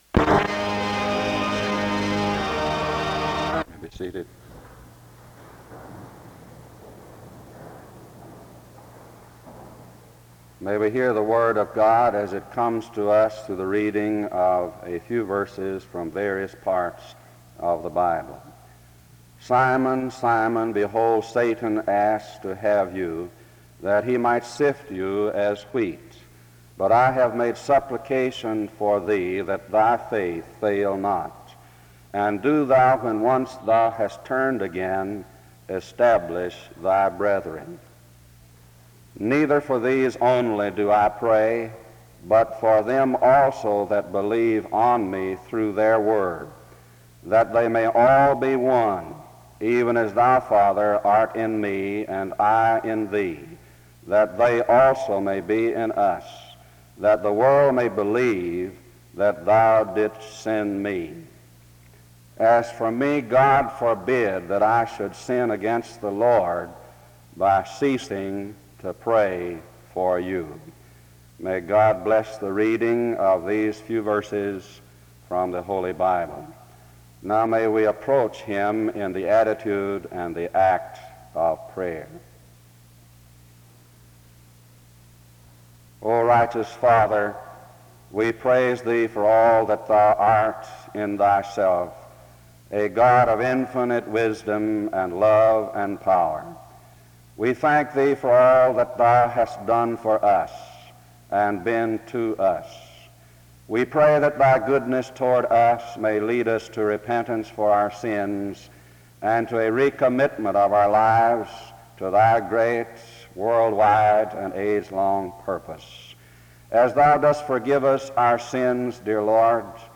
Various verses of the Bible are read (00:19-01:10), and an opening prayer occurs (01:26-02:44). Hymn #340, “The Prayer Hymn” is sung in worship (03:00-05:37).